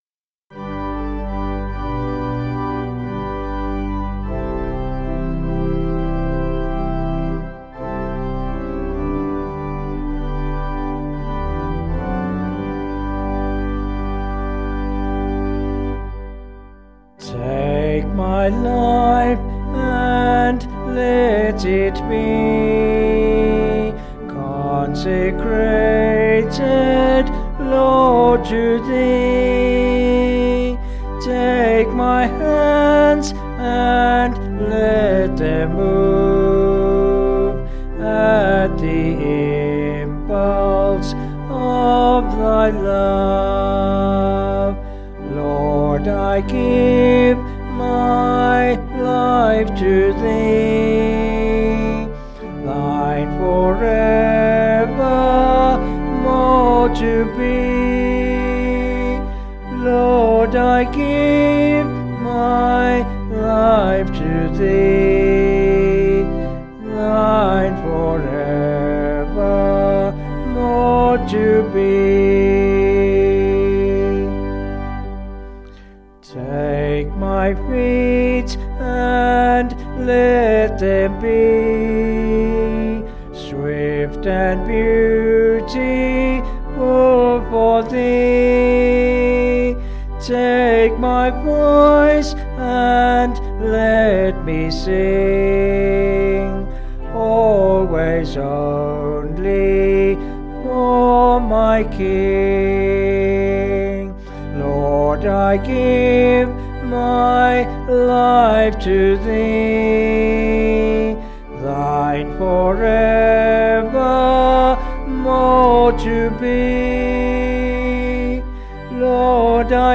Vocals and Organ   262kb Sung Lyrics